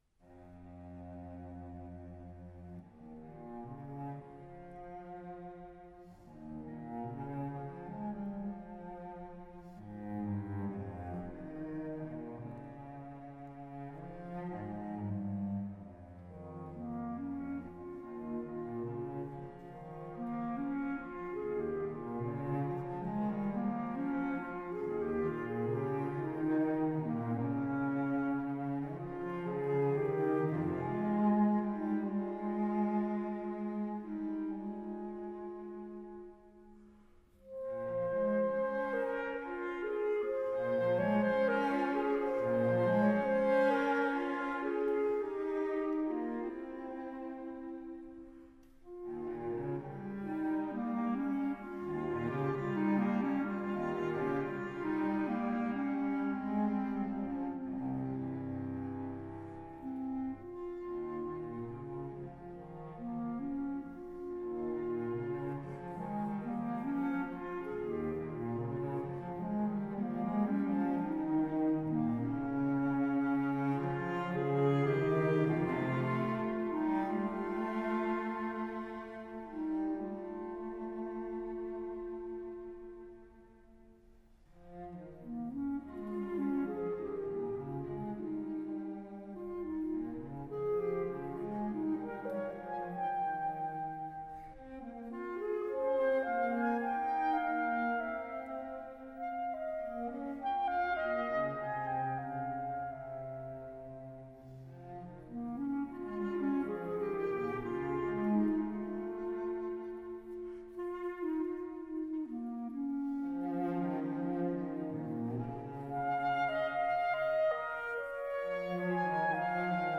Instrumentation: Clarinet and Cello
Category: Chamber Music
Ensemble: Duet
Instrument / Voice: Cello, Clarinet